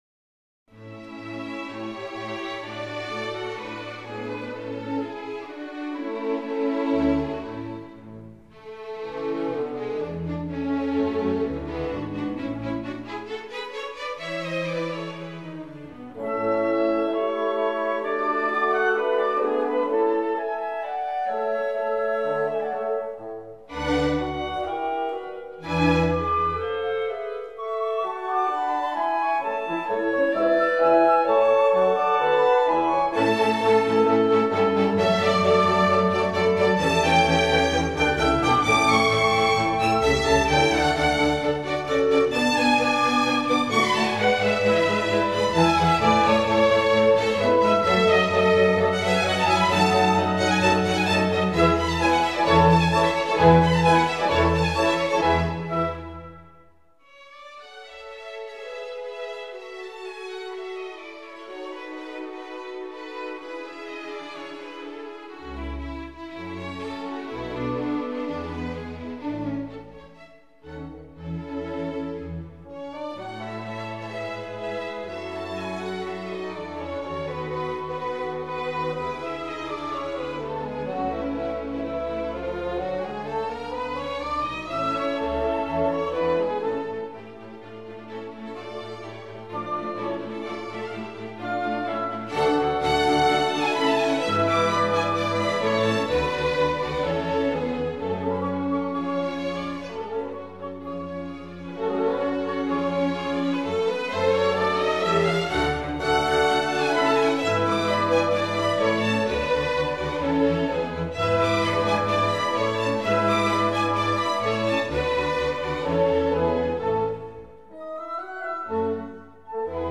موسیقی شاد و زیبا از موتسارت (شماره ۲)